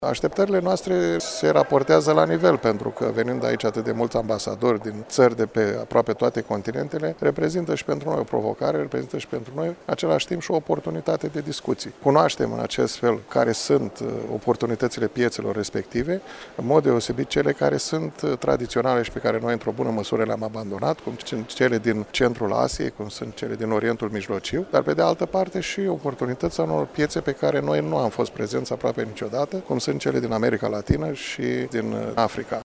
În deschiderea întâlnirii cu reprezentanţii mediului de afaceri ieşean